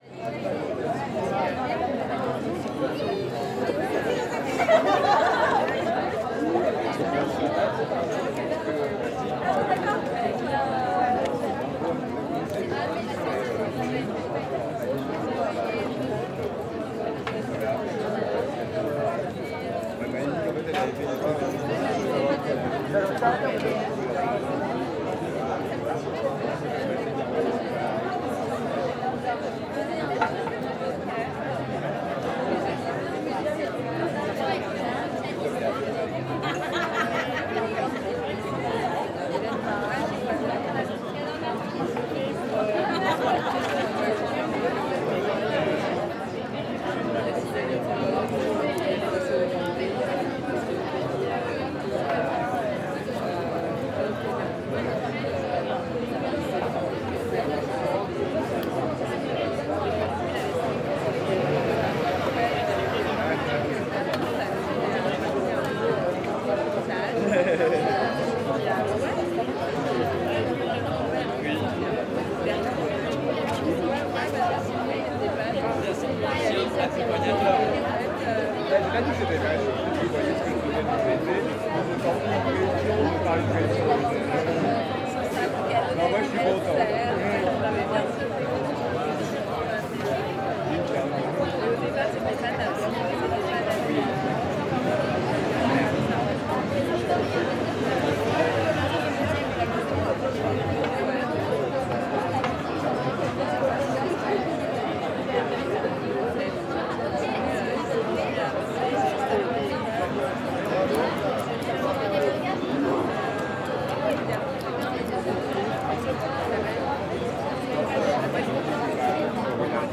Café/bar "Aux Folies", 8 rue de Belleville, 75020 Paris. Prise de son statique en extérieur, face à la terrasse
fr CAPTATION SONORE
fr Voiture
fr Vélo
fr Conversation